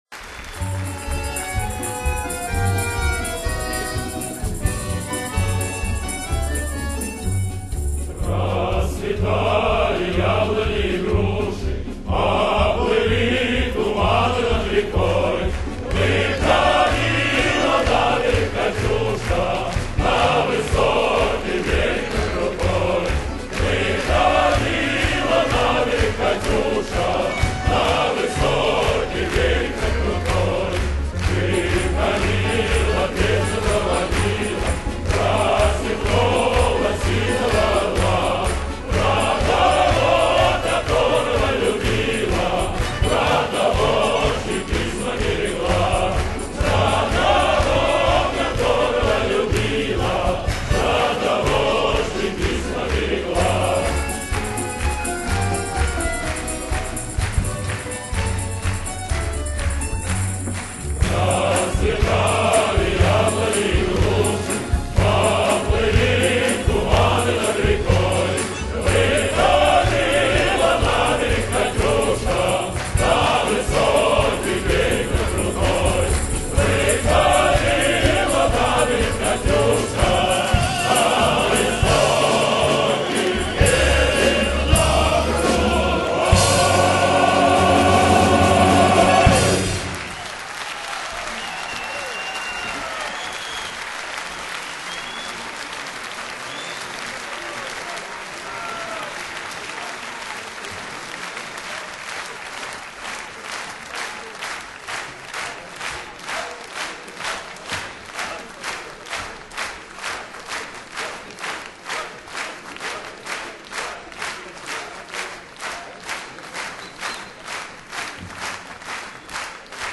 Russian Folk/Soviet Patriotic/Opera